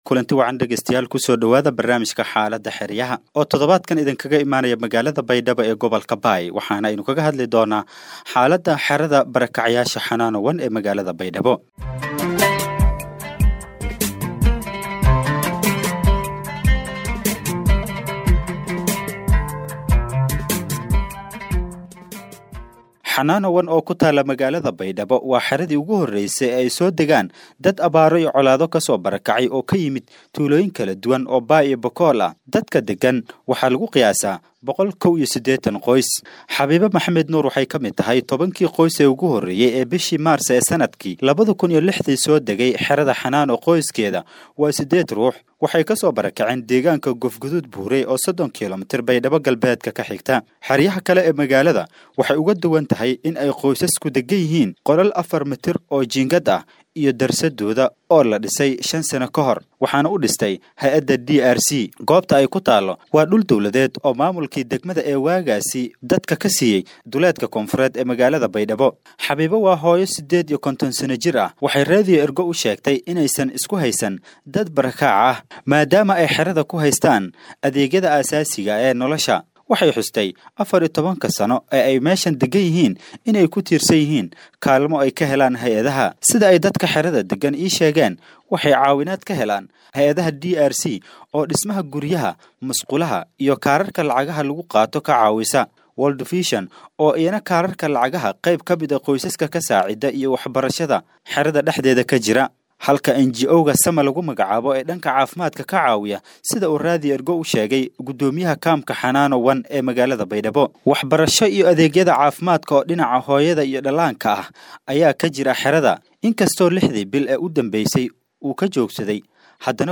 Weriyeyaasheenna ayaa booqda xeryaha kaddibna barnaamij dhinacyo kala duwan ah ka soo diyaariya, iyagoo ku saleynaya hadba waxa markaas xiisaha leh ee ay la soo kulmaan.